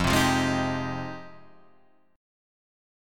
Fm9 chord